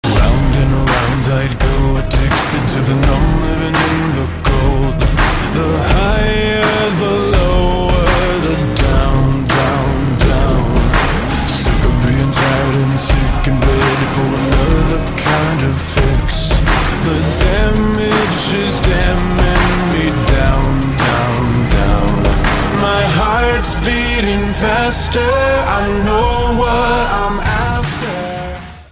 • Качество: 128, Stereo
красивый мужской голос
мотивирующие
мелодичные
Pop Rock
alternative